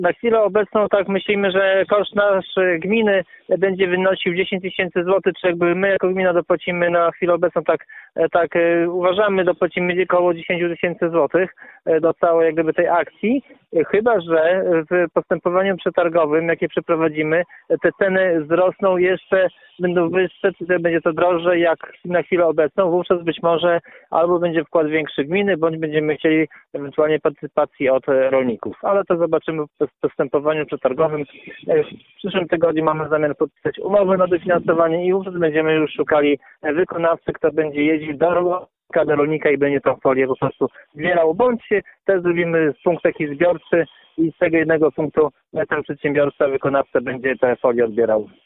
Wójt liczy, że rolnicy nie będą musieli do niczego dopłacać.